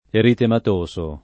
[ eritemat 1S o ]